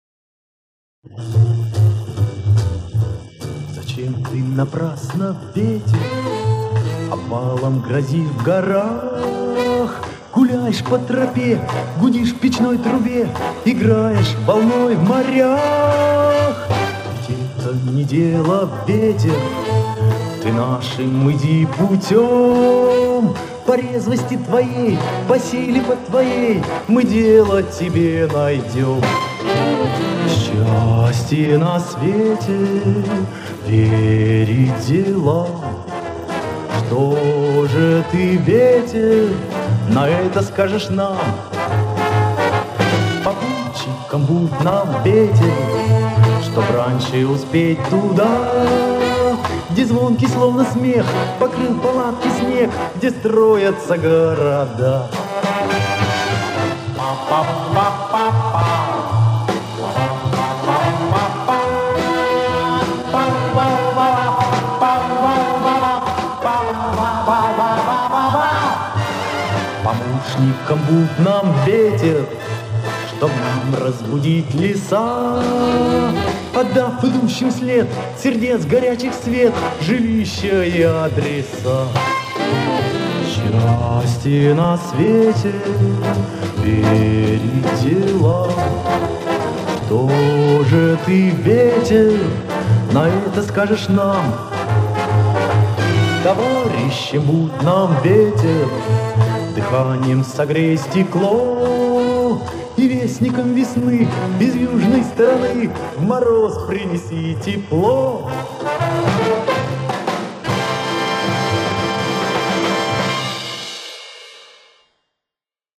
Авторское исполнение!